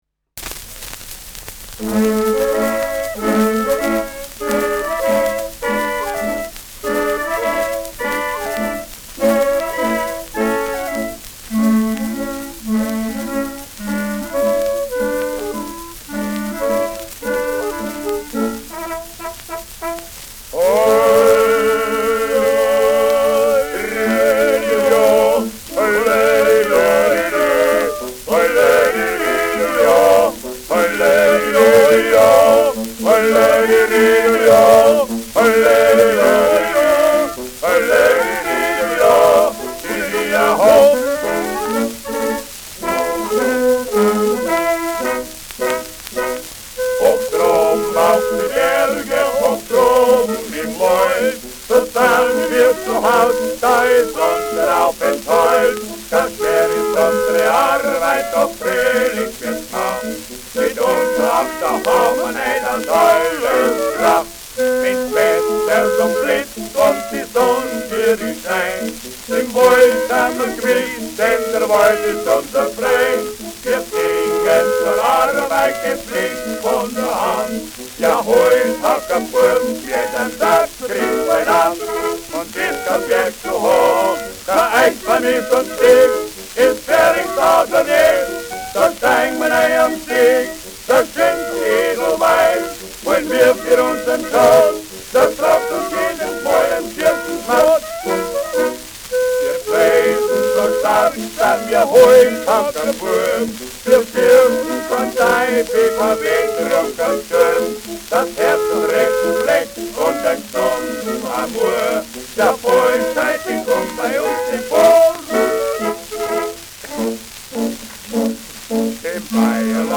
Schellackplatte
leichtes Rauschen : präsentes Knistern : leichtes Leiern
Adams Bauern-Trio, Nürnberg (Interpretation)
[Nürnberg] (Aufnahmeort)